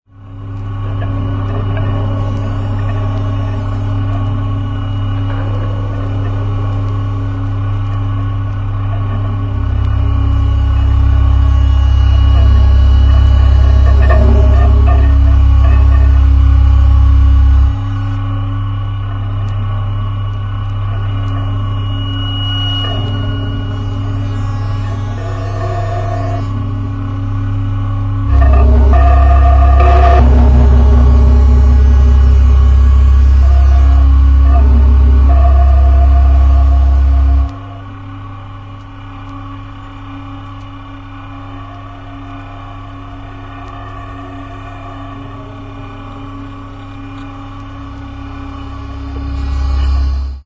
Mind/Noise , Noise , Weird Electronics